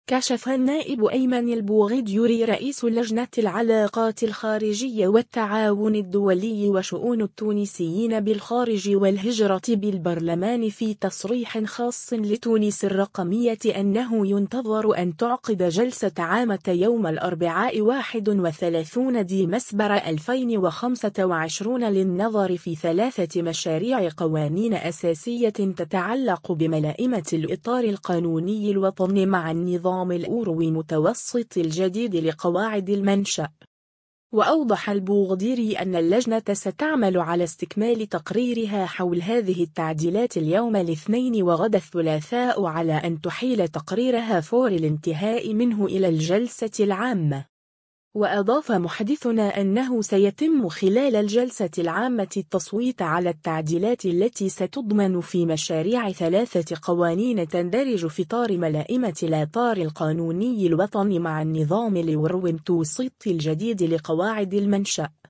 كشف النائب أيمن البوغديري رئيس لجنة العلاقات الخارجية والتعاون الدولي وشؤون التونسيين بالخارج والهجرة بالبرلمان في تصريح خاص لـ”تونس الرقمية” أنه ينتظر أن تعقد جلسة عامة يوم الأربعاء 31 ديمسبر 2025 للنظر في ثلاثة مشاريع قوانين أساسية تتعلّق بملاءمة الإطار القانوني الوطني مع النظام الأورو-متوسطي الجديد لقواعد المنشأ.